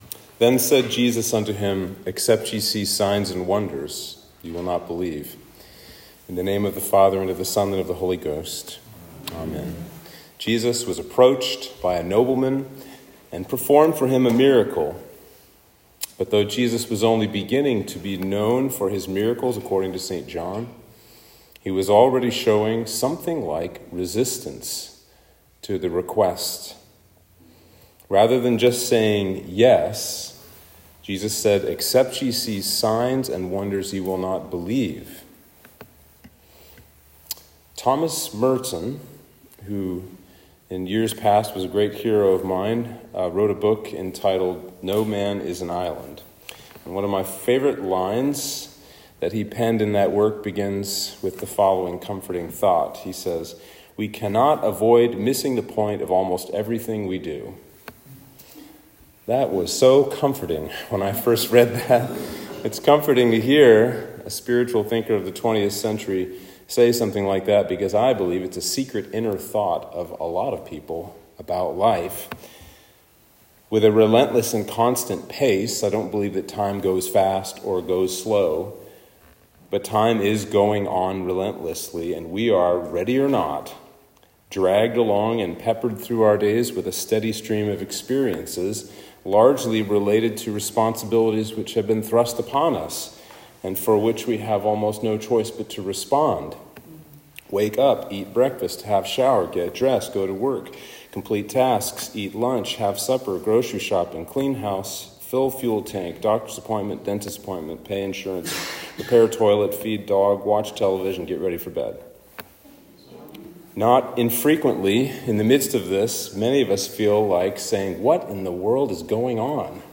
Sermon for Trinity 21